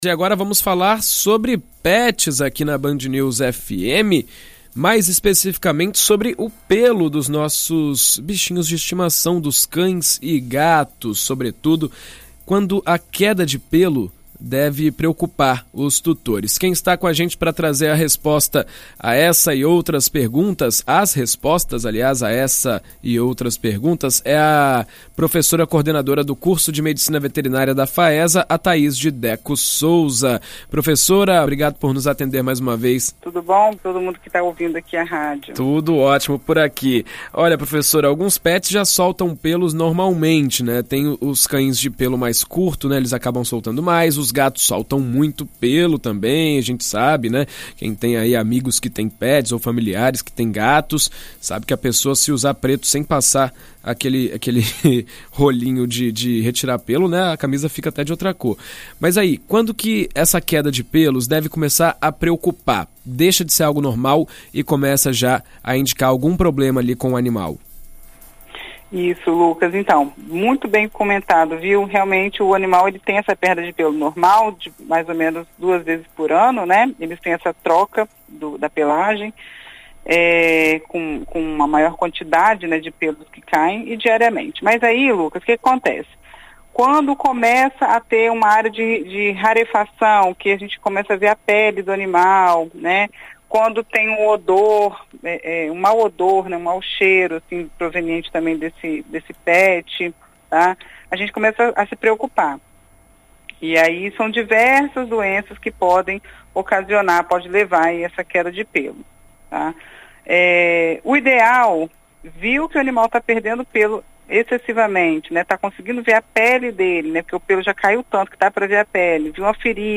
Em entrevista à rádio BandNews FM ES nesta terça-feira (28)